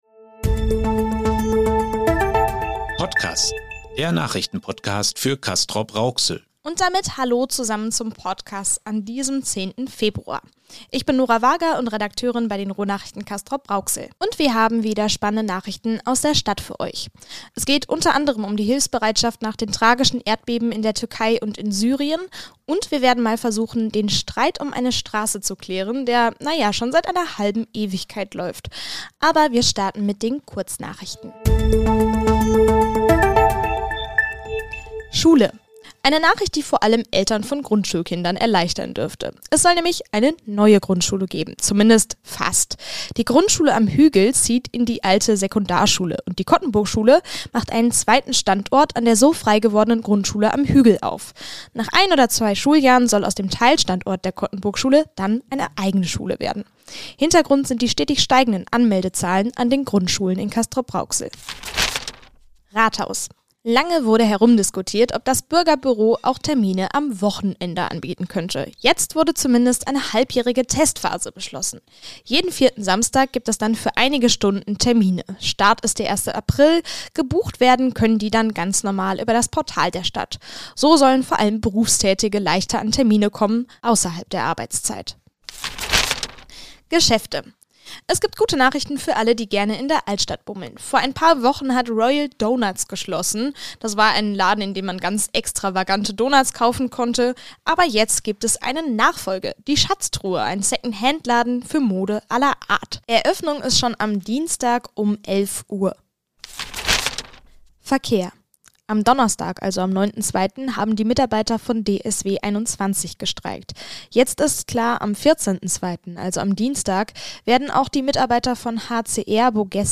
Neben diesen beiden großen Themen gibt es wieder kleine Nachrichten aus dieser Woche und Veranstaltungstipps fürs Wochenende.